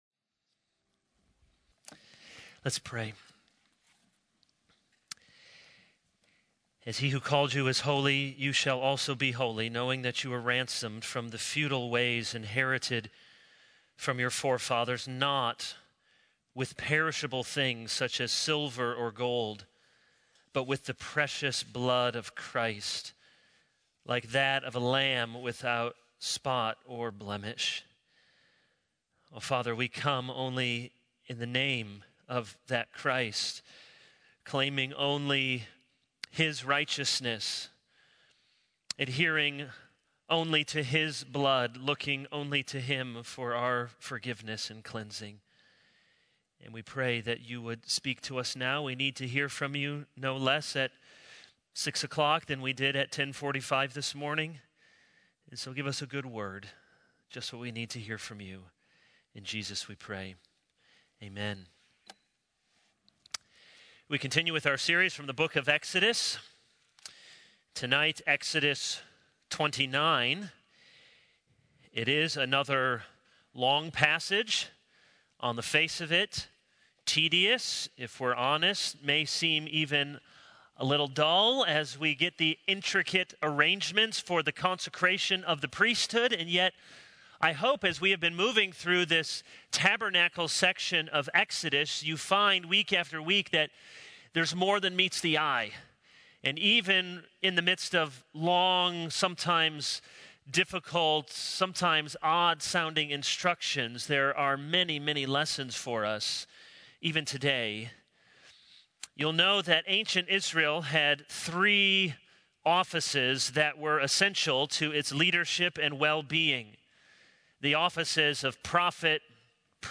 This is a sermon on Exodus 29.